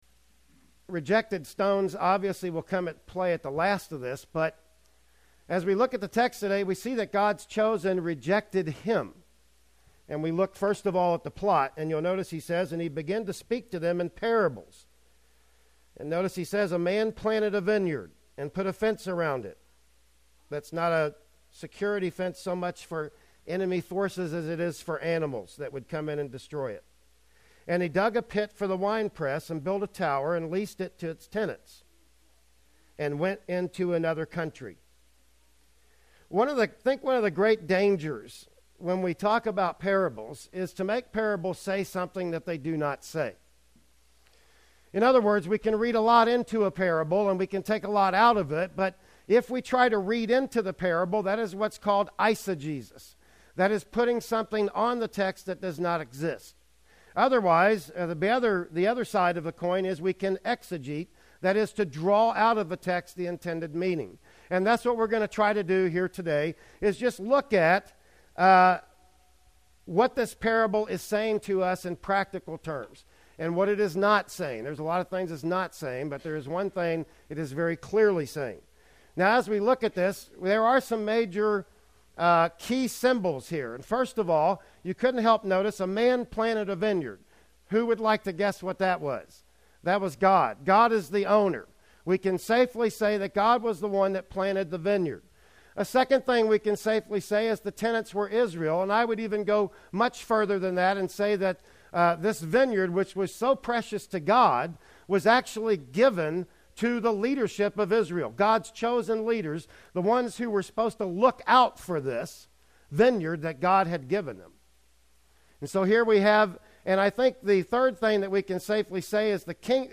"Mark 12:1-12" Service Type: Sunday Morning Worship Service Bible Text